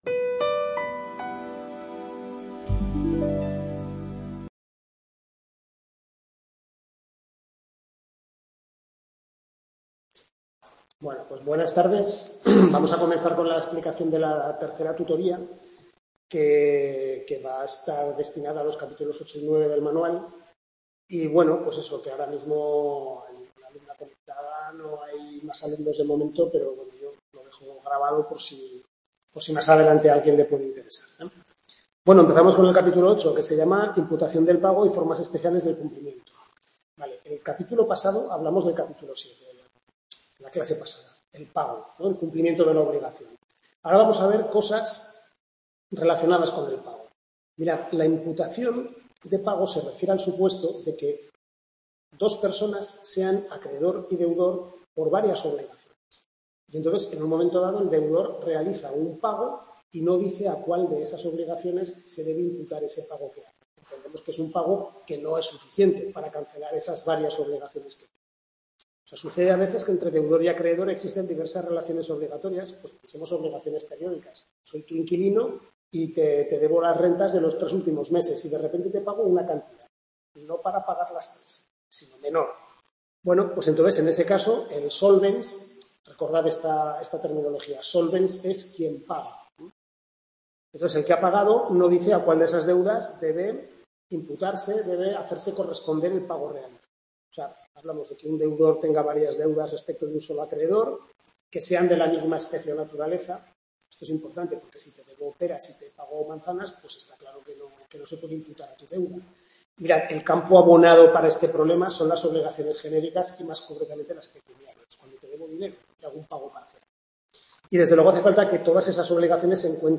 Tutoría 3/6 primer cuatrimestre Civil II (Obligaciones)